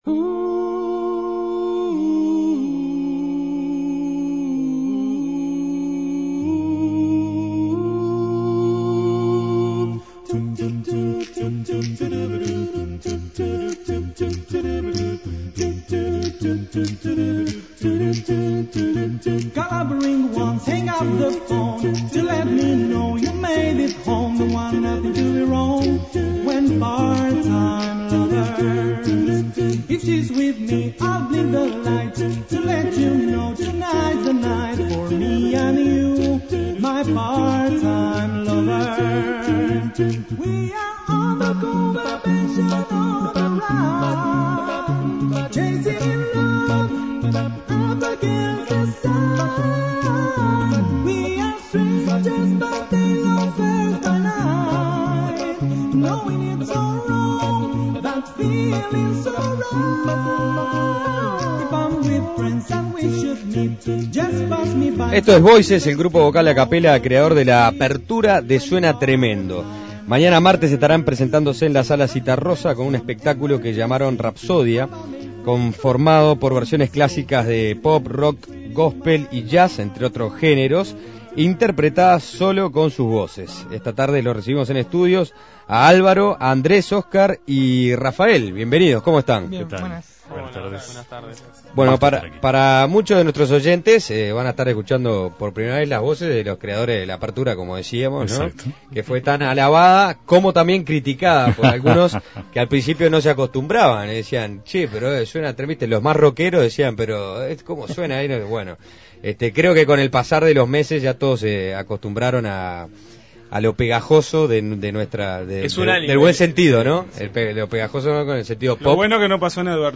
Voices es un grupo vocal a capella creador de la apertura de Suena Tremendo. Este martes estarán presentándose en la Sala Zitarrosa con un espectáculo que llamaron "Rapsodia" conformado por versiones clásicas pop, rock, gospel y jazz, entre otros géneros, interpretadas solo con sus voces.